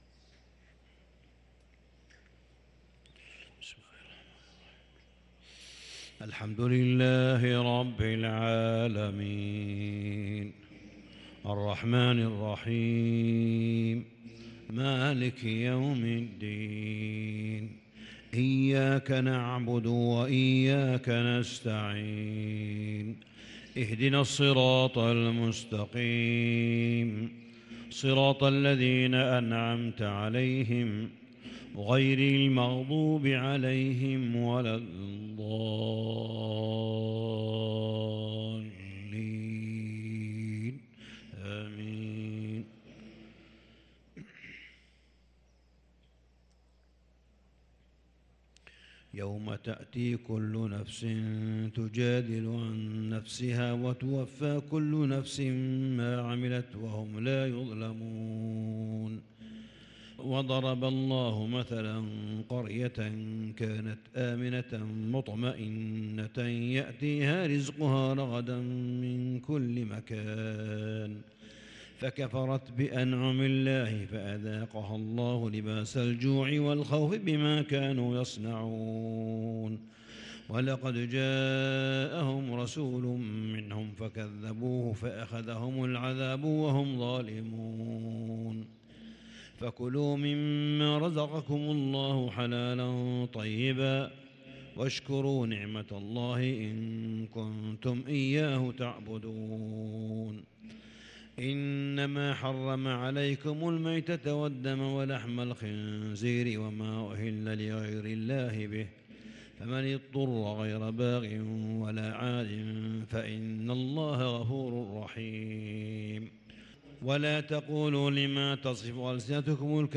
صلاة الفجر للقارئ صالح بن حميد 26 رمضان 1443 هـ
تِلَاوَات الْحَرَمَيْن .